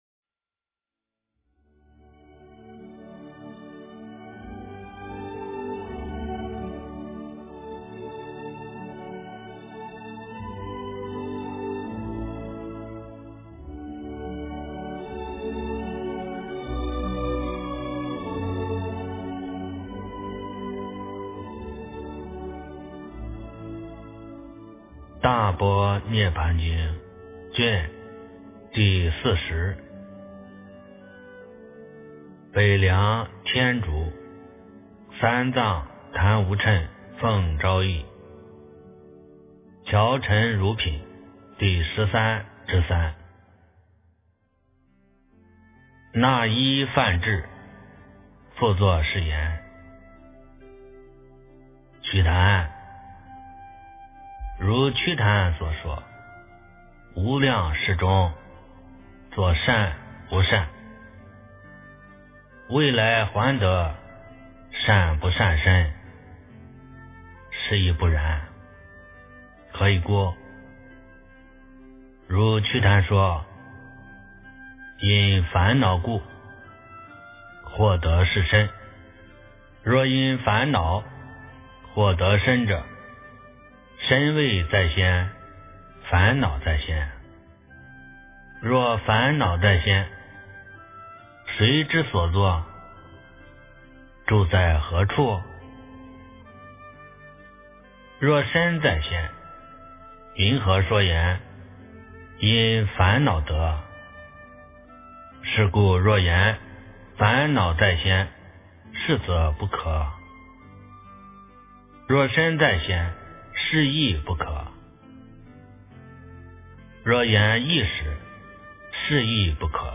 诵经